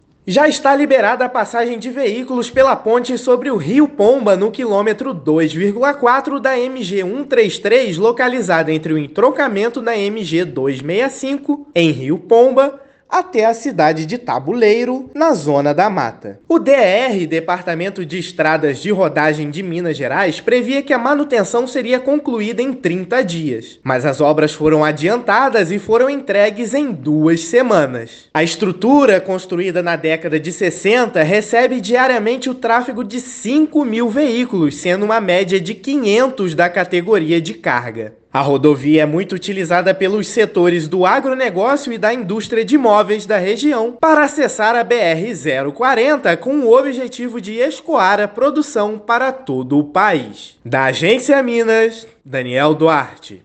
Trabalho de recuperação da estrutura foi realizado em apenas 15 dias. Ouça a matéria de rádio: